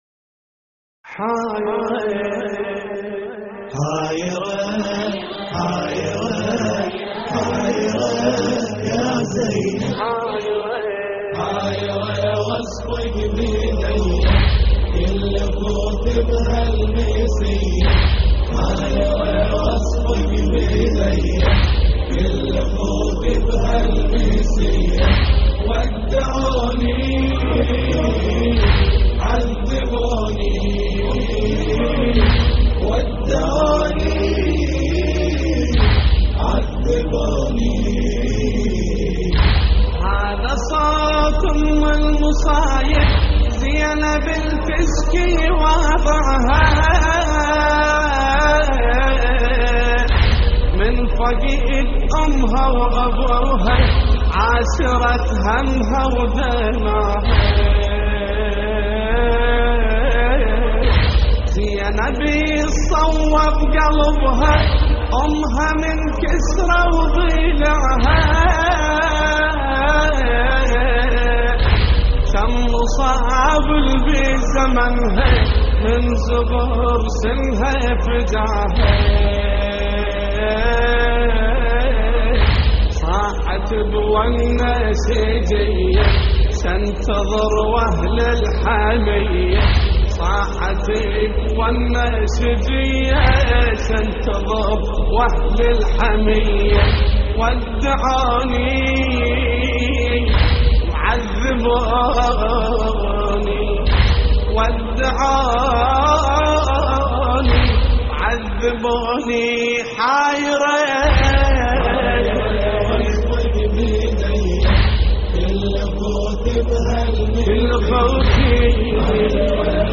تحميل : حايرة واصفك بإيدية كل أخوتي بهل المسيه ودعوني / مجموعة من الرواديد / اللطميات الحسينية / موقع يا حسين
اللطميات الحسينية حايرة واصفك بإيدية كل أخوتي بهل المسيه ودعوني - استديو